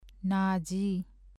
ဏကြီး [nâ-jí ]子音字「ဏ」の名前。